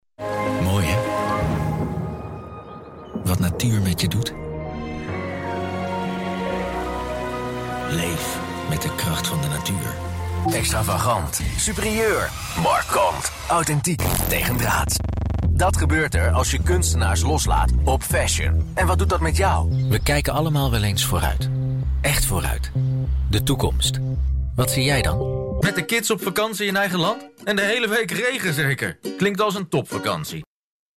moedertaal: nederlands mannenstem levering per e-mail mogelijk ervaring:zeer ervaren
klankleeftijd:klankleeftijd 25-40klankleeftijd 40-55klankleeftijd 55-70
producties:nieuwspromo - commercialdocumentaire - bedrijfsfilmstemmetjes - cartoonsgesproken boek
Zoekt u een warme mannenstem voor uw commercial, e-learningvideo, luisterboek, voicemail, documentaire, hoorspel, audiotour of wat u ook maar in gedachten heeft?